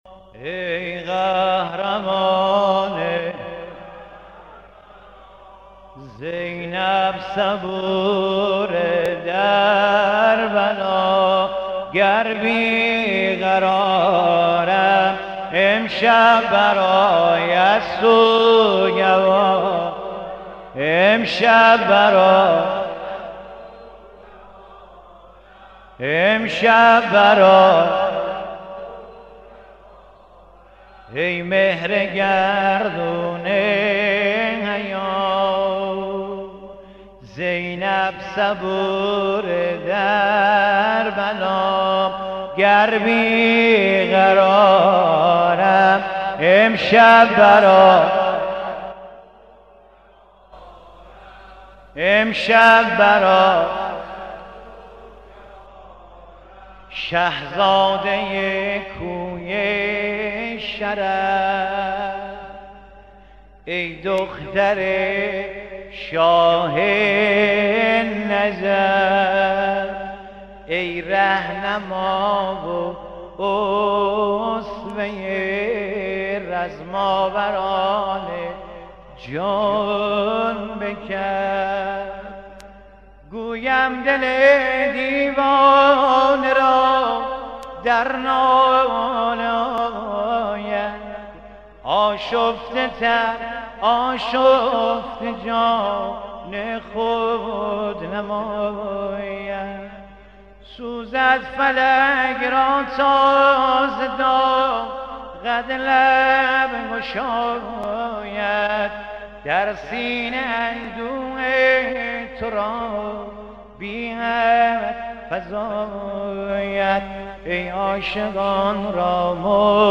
0 0 زمزمه گر بی قرارم امشب برایت سوگوارم